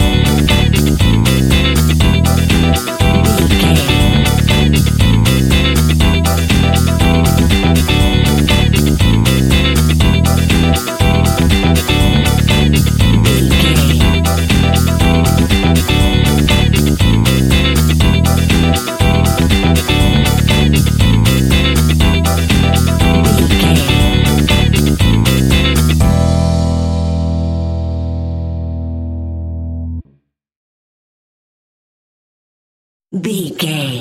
Aeolian/Minor
groovy
uplifting
energetic
drums
bass guitar
electric piano
synthesiser
electric guitar
brass
disco house
electronic funk
upbeat
synth leads
Synth Pads
synth bass
drum machines